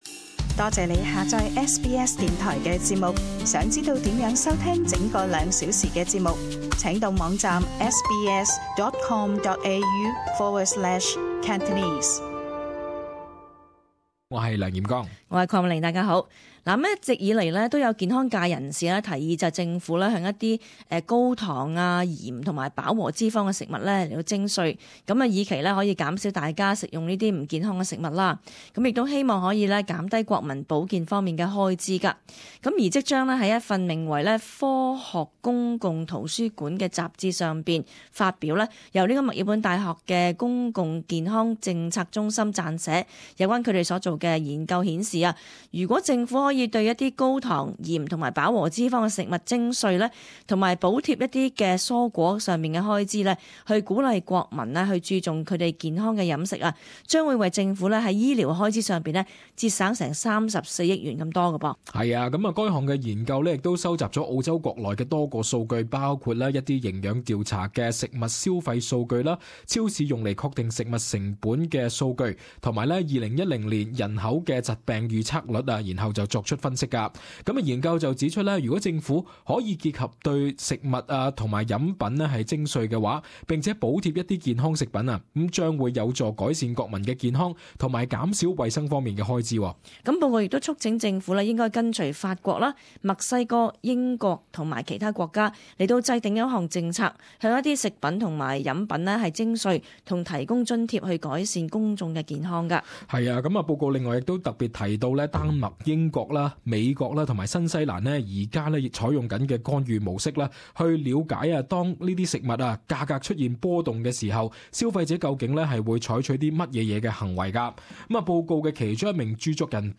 【時事報導] 向含糖食品徵稅可令人長壽？